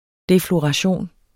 Udtale [ defloʁɑˈɕoˀn ]